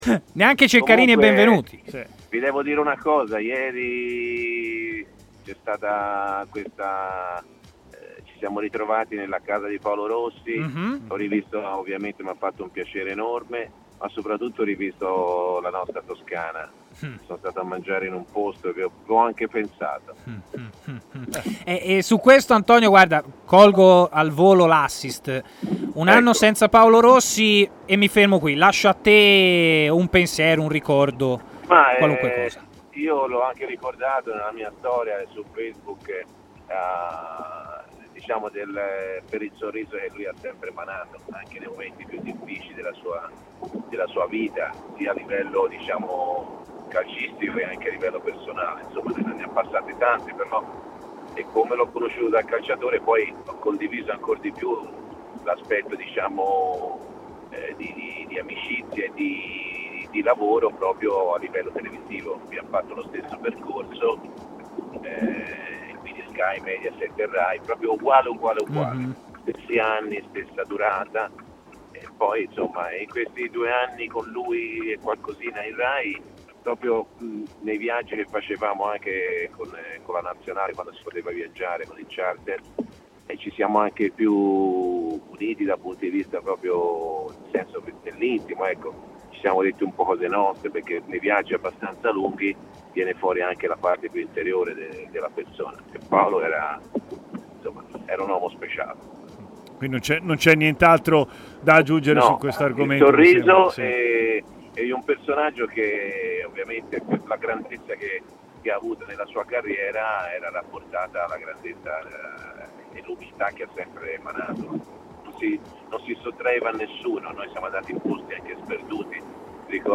L'opinionista Antonio Di Gennaro è intervenuto in diretta a Stadio Aperto, trasmissione di TMW Radio